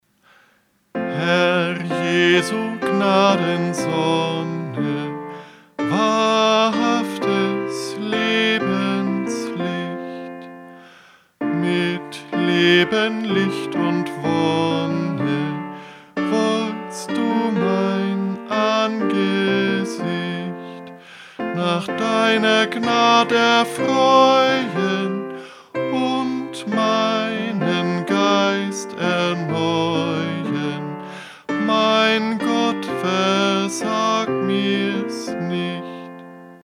Eingesungen: Liedvortrag (